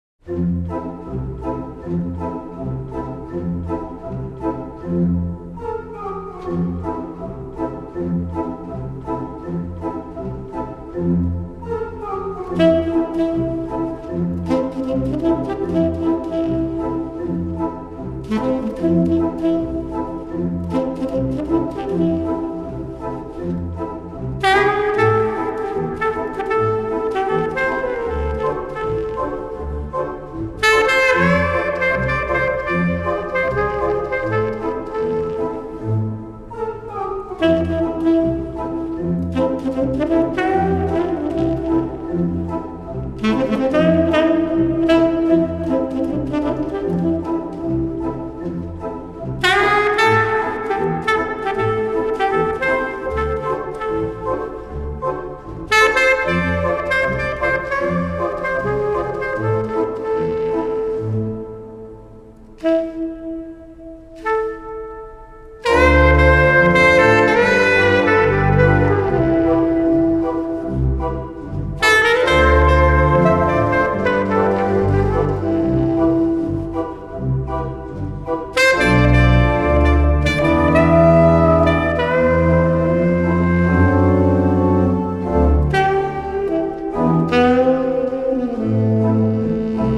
爵士及藍調
★ 音場深邃寬廣、音質細膩飽滿、兼且定位清晰，兩種屬性不同的樂器竟然可以和諧地表現出如此美妙的天籟之聲！